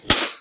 partypopper.ogg